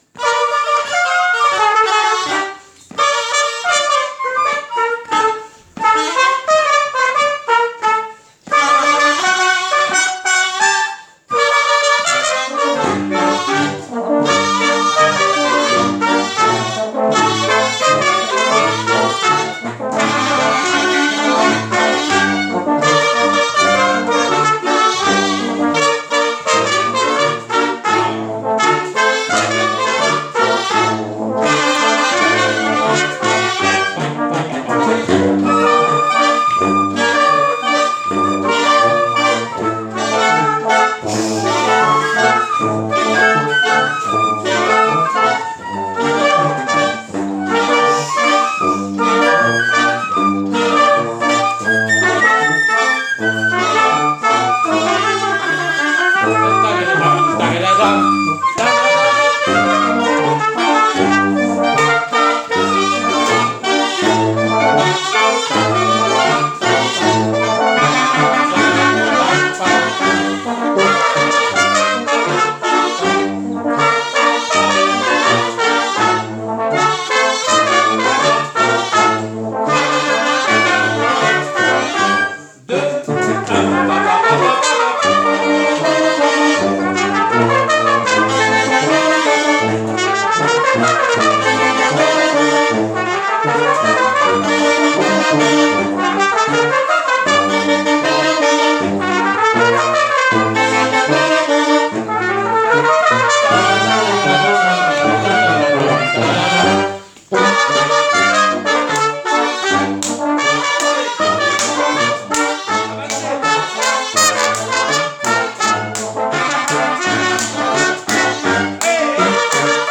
Enregistrement du 15 mars (1ere fois qu’on le répète …!) :